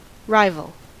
Ääntäminen
IPA : /ˈraɪvəl/